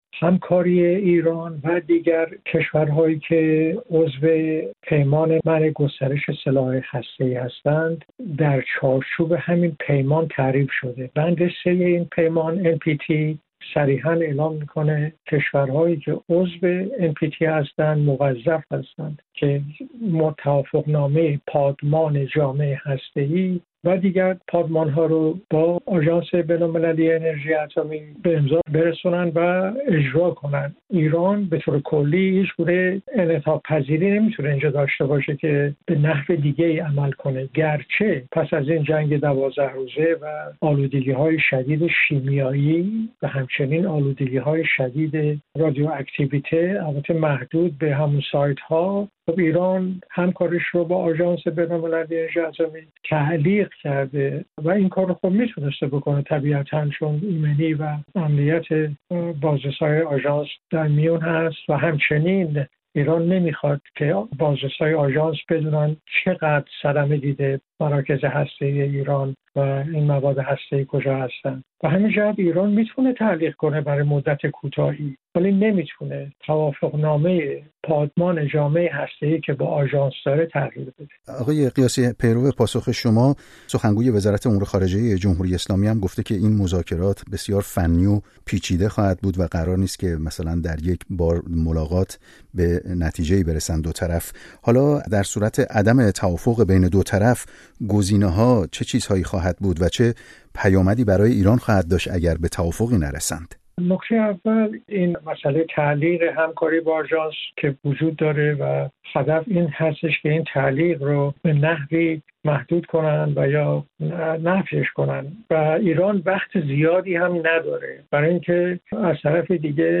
مذاکرات «پیچیده» آژانس با ایران در گفت‌وگو با استاد حقوق بین‌الملل هسته‌ای